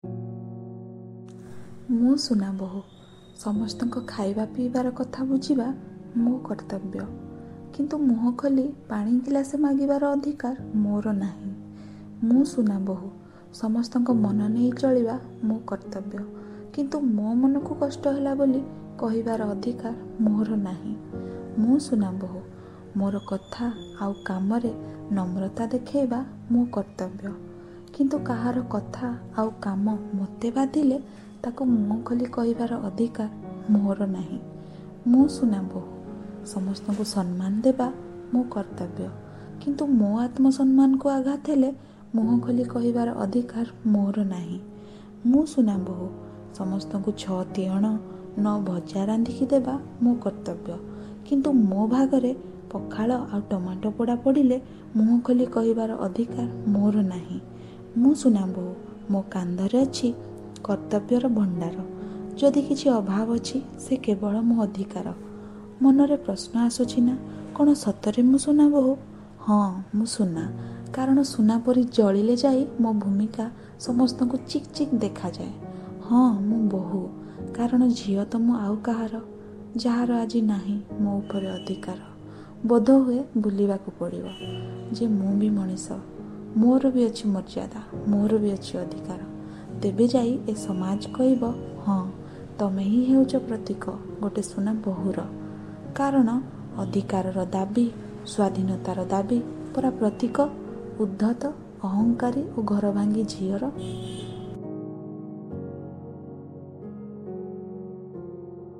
କବିତା : ମୁଁ ସୁନାବୋହୁ